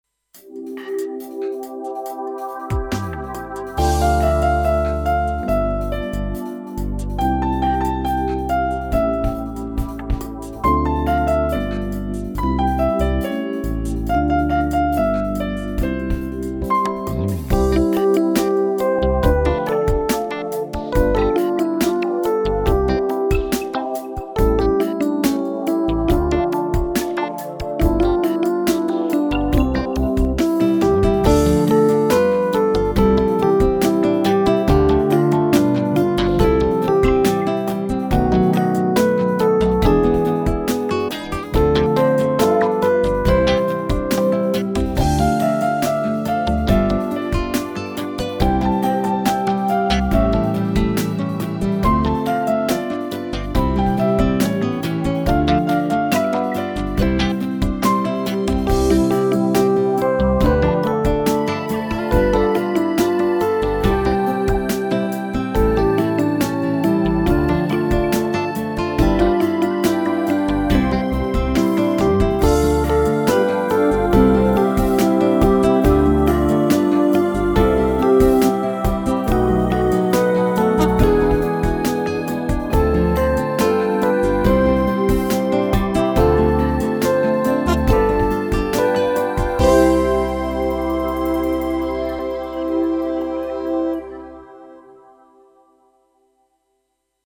• Категория: Детские песни
караоке
минусовка